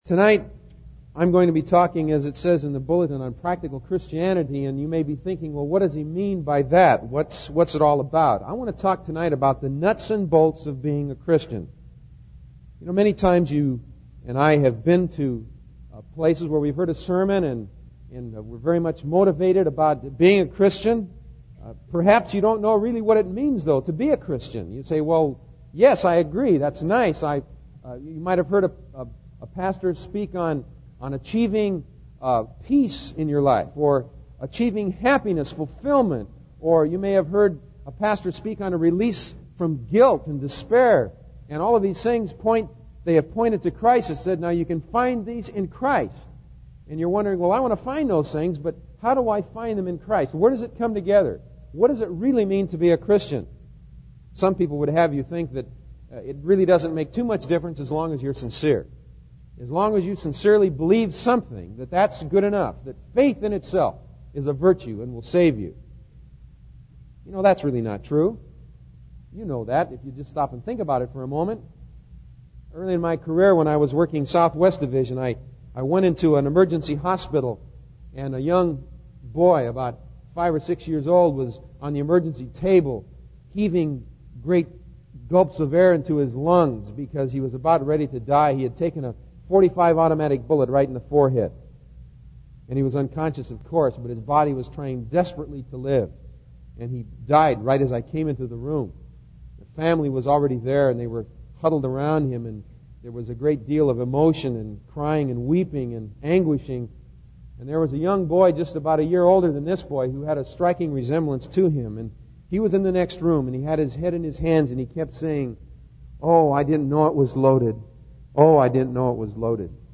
In this sermon, the speaker emphasizes the importance of receiving Jesus as one's personal savior.